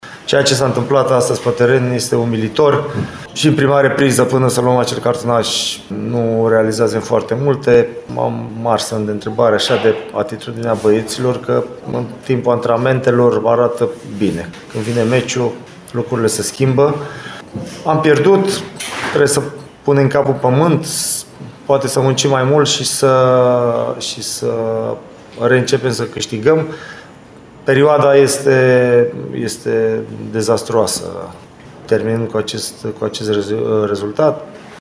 De partea cealaltă, la sinteza declarațiilor, actualul tehnician utist Adrian Mihalcea a considerat rezultatul umilitor: